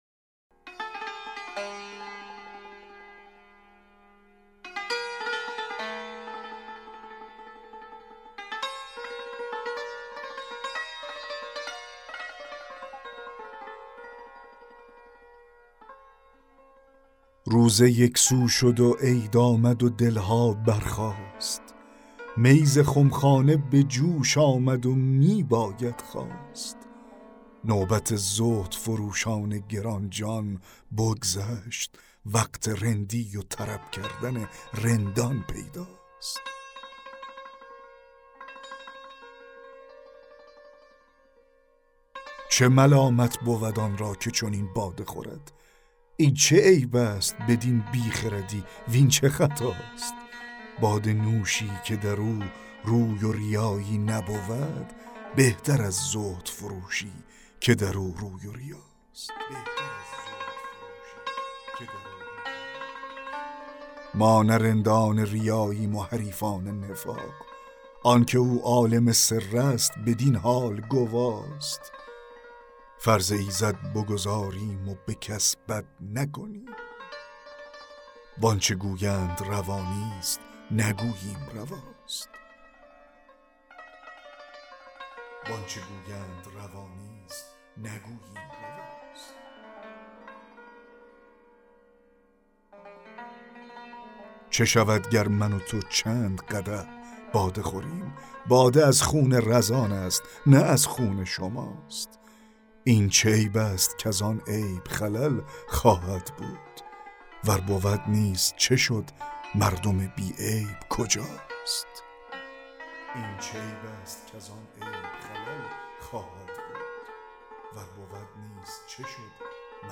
دکلمه غزل 20 حافظ
در دکلمه آکسان روی “نوش” است
زهدفروشی: یا یای نکره است به معنای یک زهدفروش و در دکلمه آکسان روی “روش” است